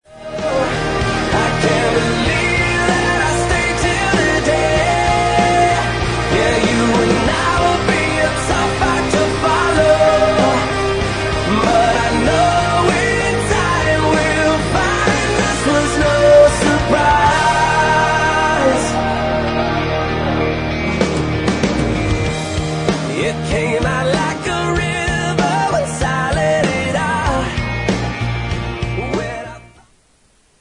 • Rock Ringtones
American rock band